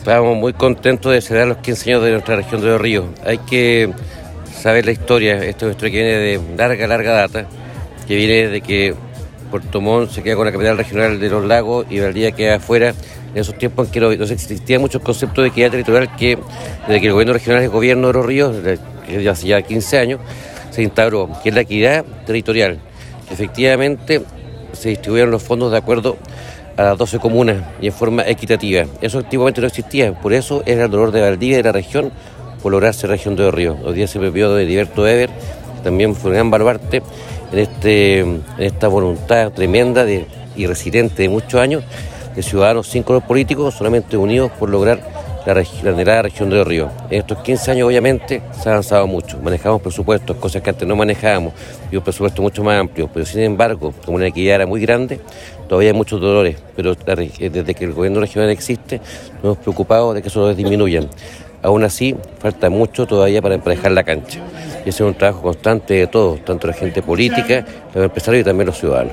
En el Teatro Regional Cervantes se llevó a cabo la ceremonia de conmemoración del 15º Aniversario de la Región de Los Ríos, encabezada por el Gobernador Regional Luis Cuvertino Gómez, la cual se realizó bajo el alero de la sesión extraordinaria N°114 del Consejo Regional de Los Ríos, donde tradicionalmente los Consejeros y Consejeras Regionales entregan medallas en homenaje a personas e instituciones destacadas por su aporte al desarrollo regional.
A su turno, el Consejero Regional, Juan Taladriz dijo, “hoy estamos celebrando un logro ciudadano, que ha permitido generar una equidad territorial y mejor distribución de los recursos.
Cuña_Juan-Taladriz_ceremonia-15°-aniversasrio.wav